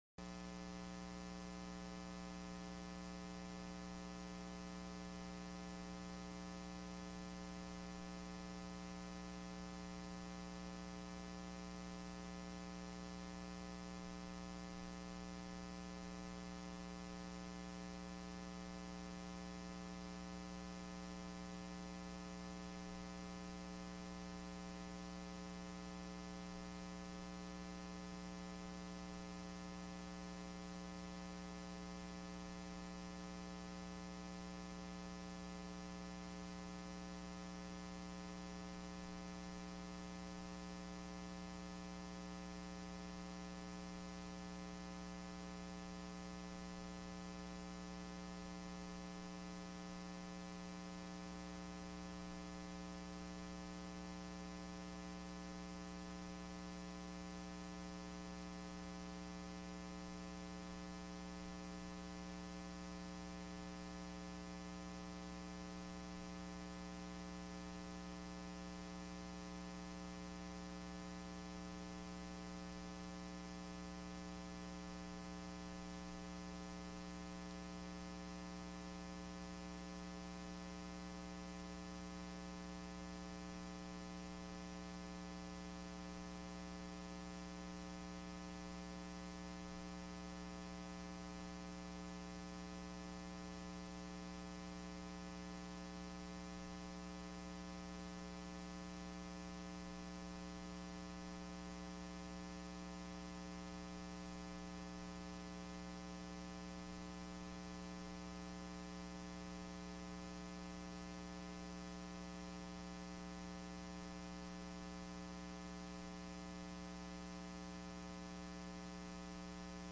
Friday Khutbah - "Good Family Relations"